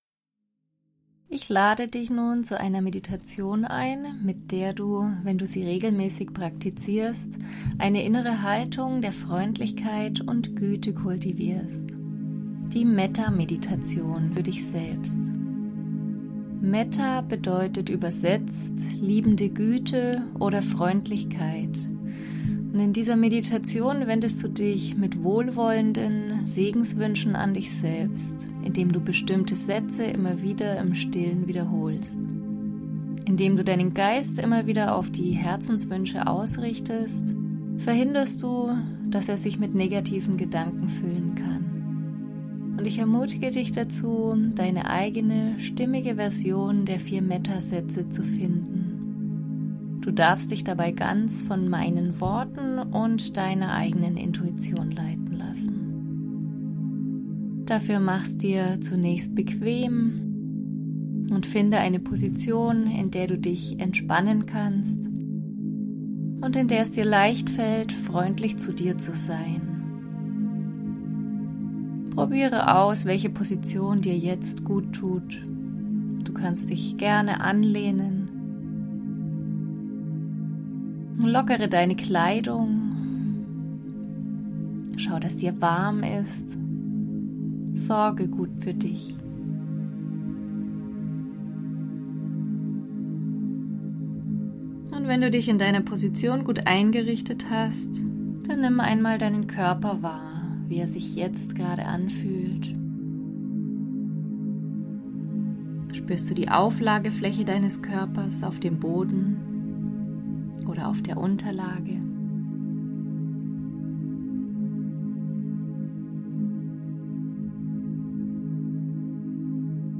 Metta-fuer-sich-selbst-mit-Musik.mp3